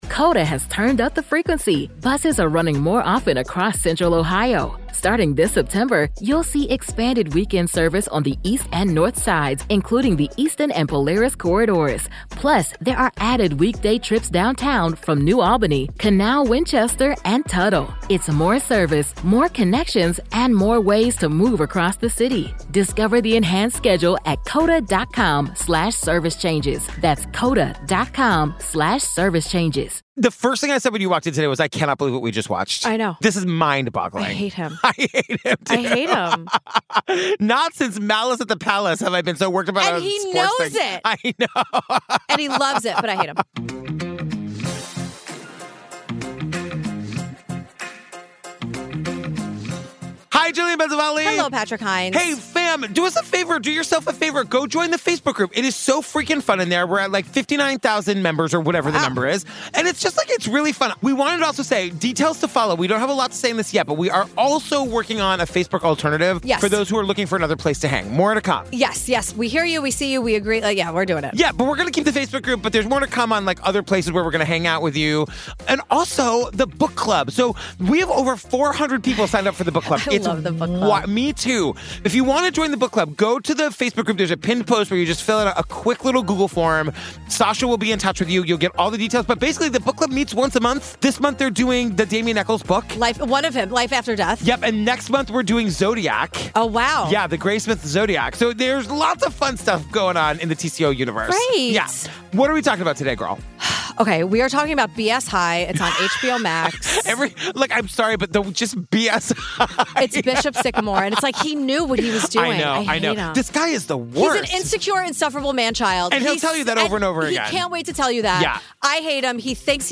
And we yell a lot in this one.